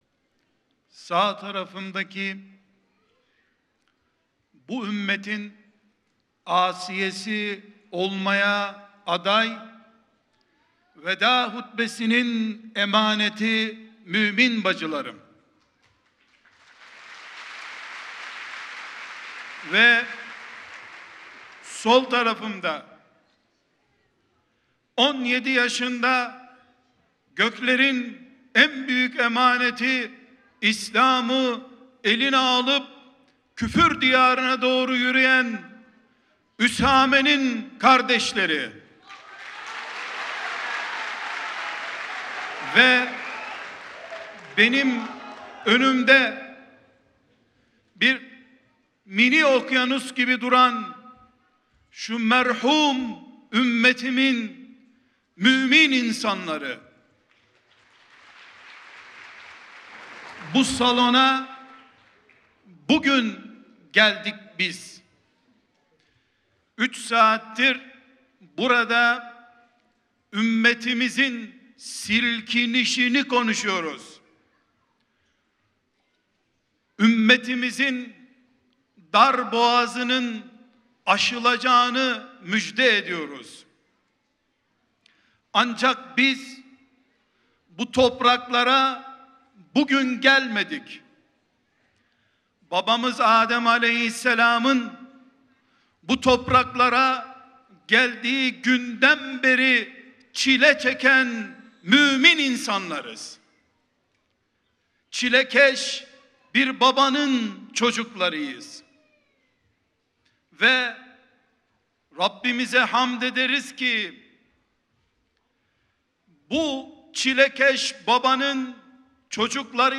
2-dirilis-bulusmalari-malatya-konusmasi.mp3